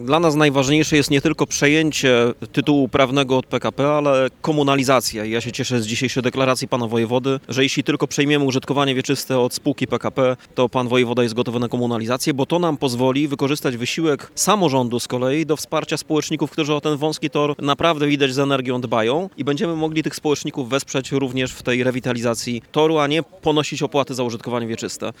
O istocie tego przedsięwzięcia oraz planach na przyszłość mówili Wojewoda Zachodniopomorski Adam Rudawski oraz Prezydent Stargardu Rafał Zając podczas wspólnego briefingu.